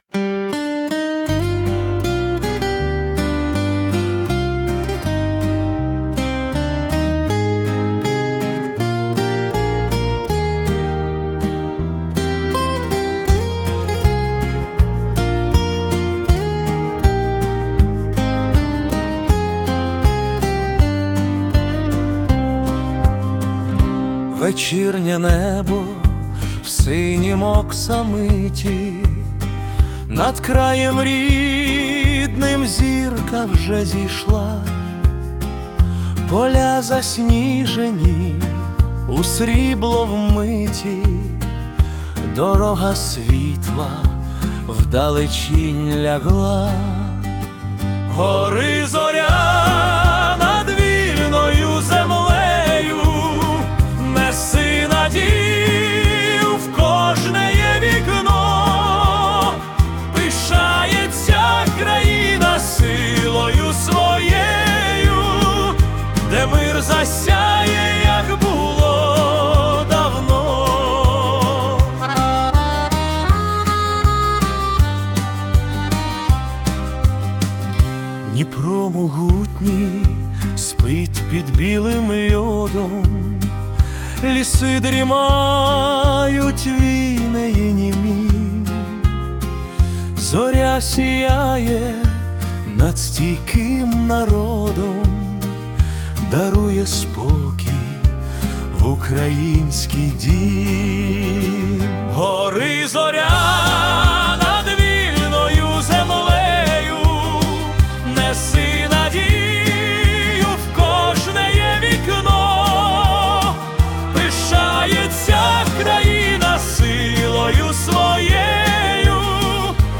🎵 Жанр: Новорічна балада
це велична і спокійна музична картина української зими.